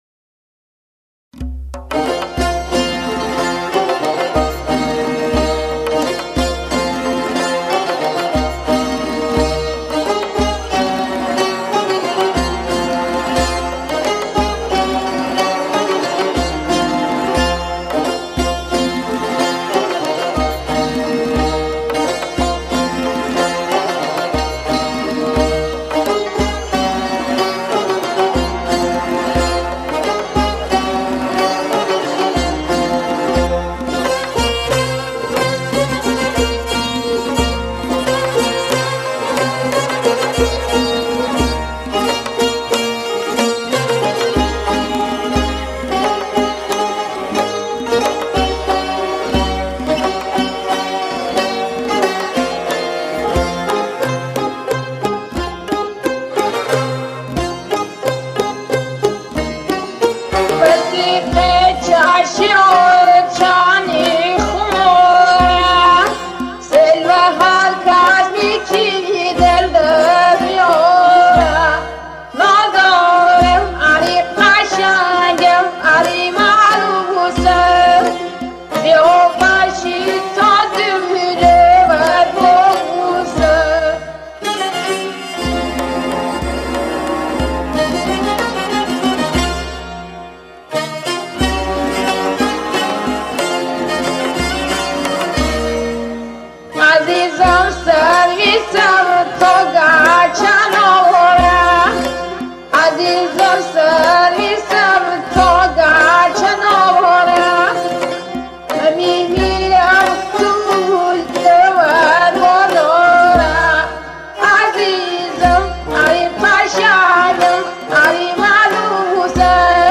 موسیقی لری
خوانندگان لری خرم آباد